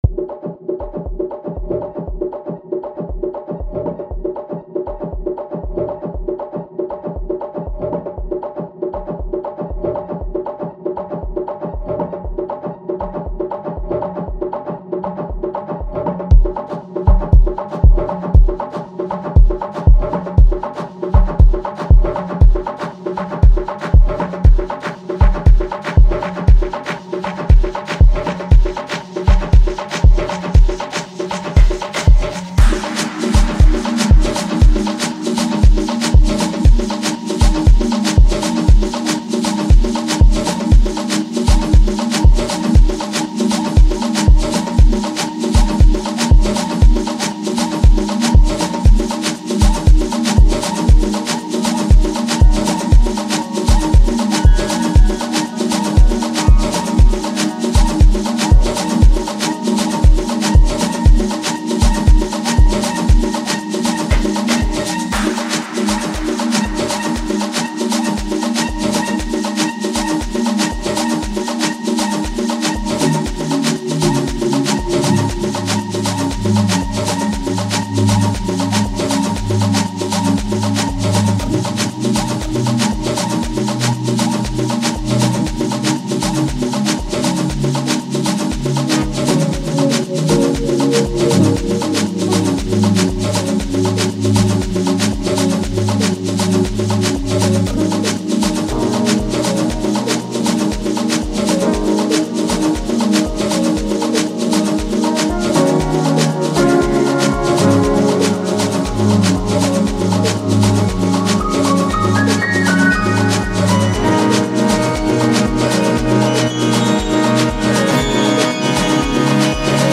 heartwarming